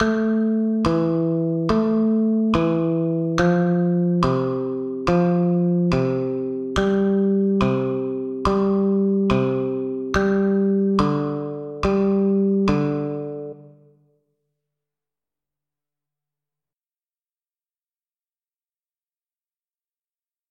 para flauta, y xilófonos.